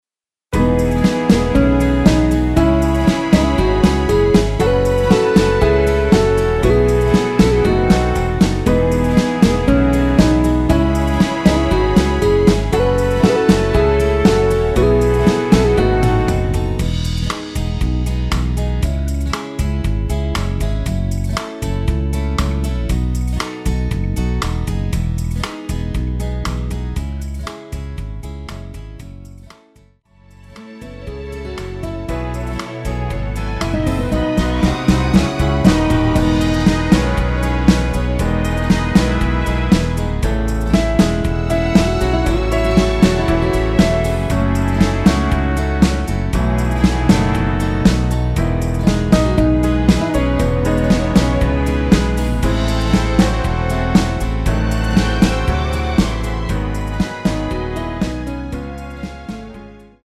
대부분의 남성분이 부르실수 있는 키로 제작 하였습니다.
앞부분30초, 뒷부분30초씩 편집해서 올려 드리고 있습니다.
중간에 음이 끈어지고 다시 나오는 이유는